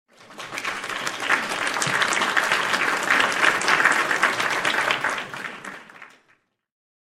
Applause Sound Effect Free Download
Applause